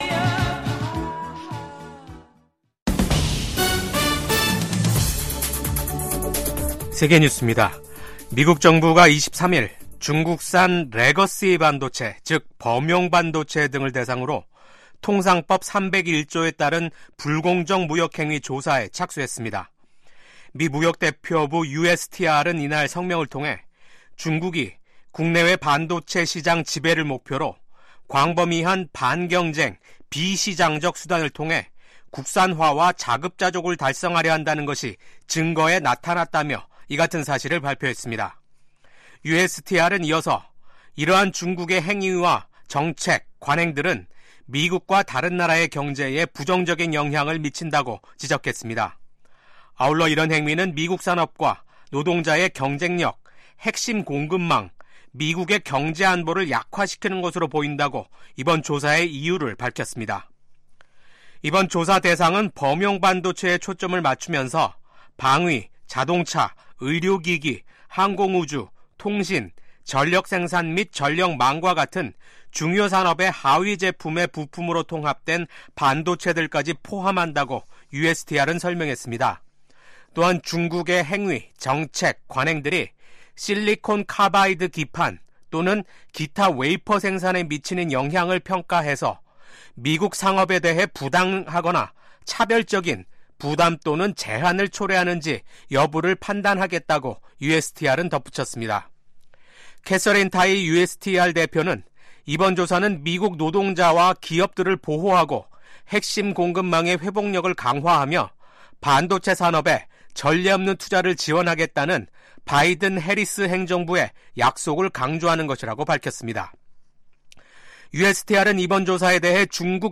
VOA 한국어 아침 뉴스 프로그램 '워싱턴 뉴스 광장'입니다. 한국 군 당국은 북한이 러시아에 추가 파병을 하고 자폭형 무인기 등을 지원하려는 동향을 포착했다고 밝혔습니다.